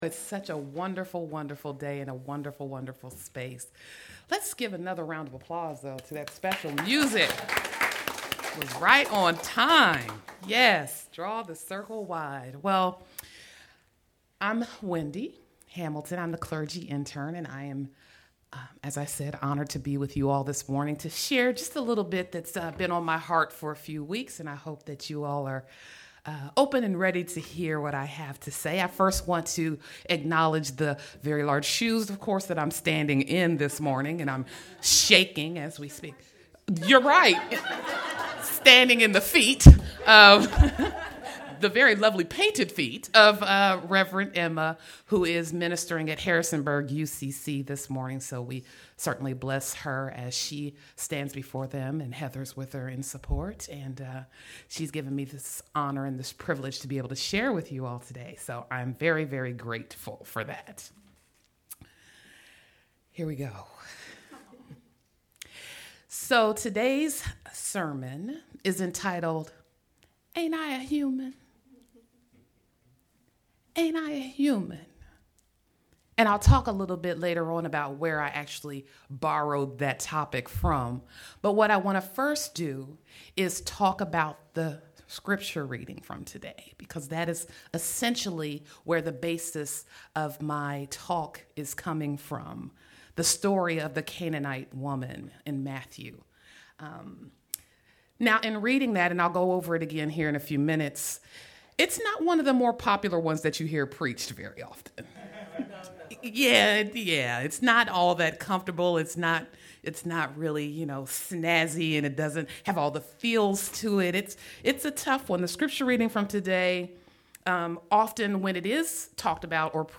9/10 Sermon Posted (Click to listen) Reflection: Ain’t I a Human?